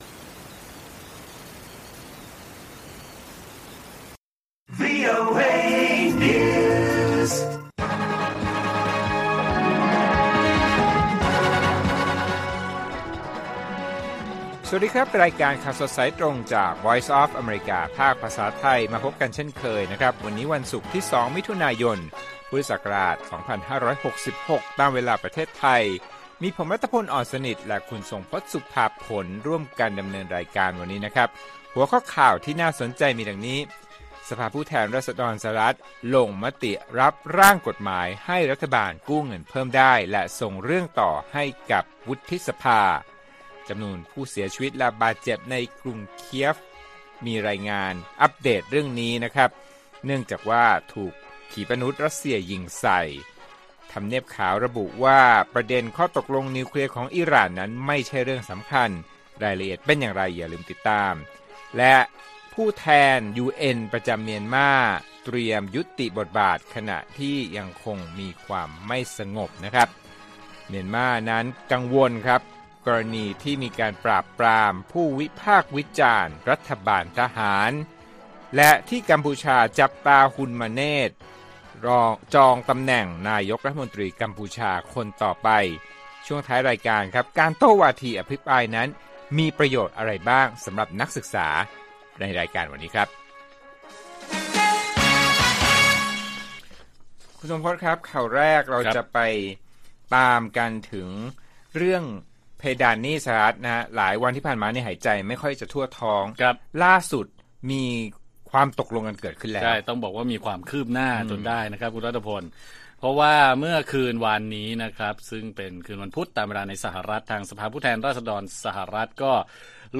ข่าวสดสายตรงจากวีโอเอไทย 6:30 – 7:00 น. วันที่ 2 มิ.ย. 2566